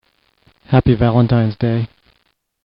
Happy Valentine's Day- Male Voice